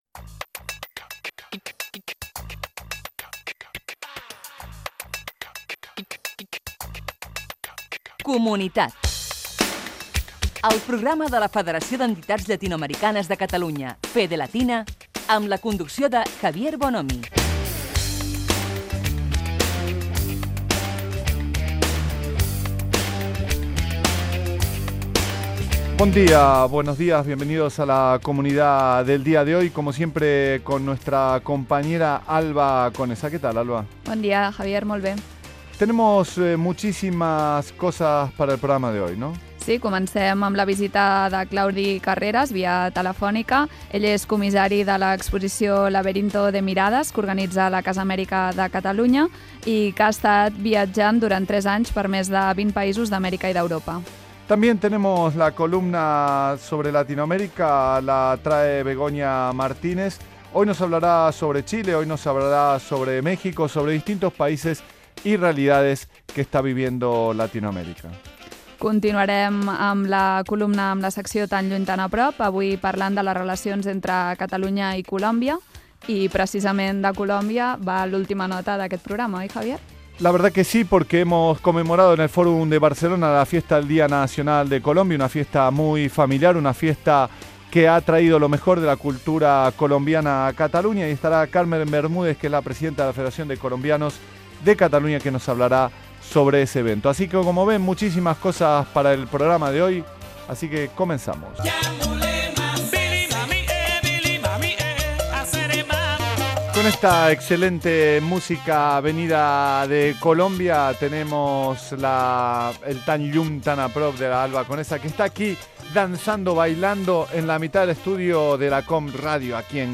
Divulgació
Fragment extret de l'arxiu sonor de COM Ràdio.